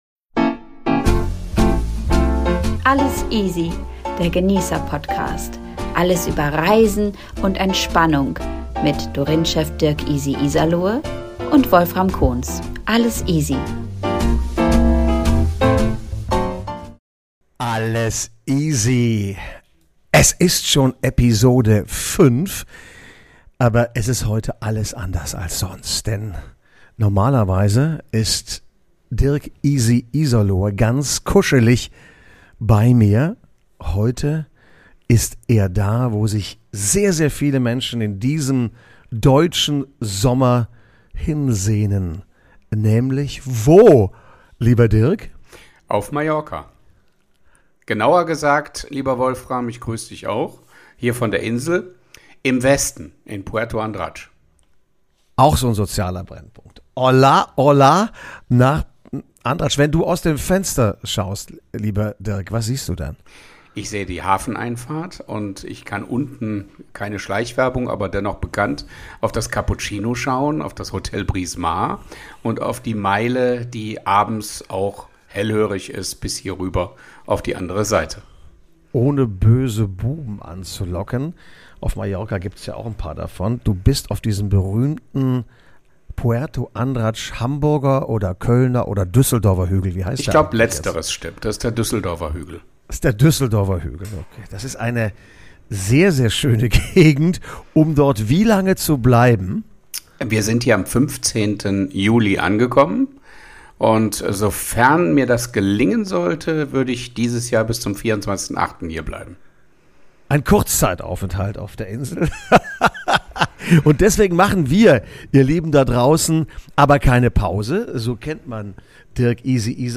Unser Summerspecial aus einer der schönsten Buchten der Sehnsuchts-Insel.